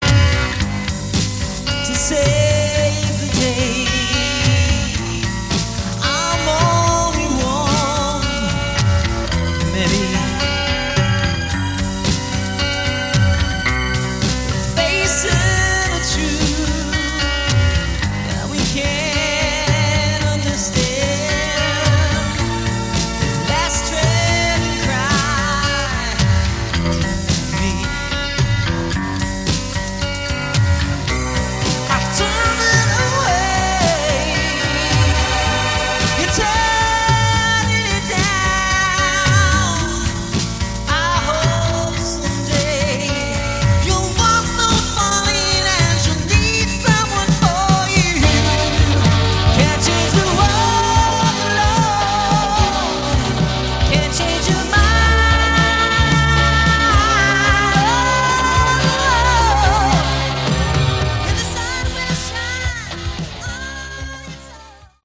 Category: AOR
keyboards & synthesizers
guitar & vocals
bass & Taurus pedals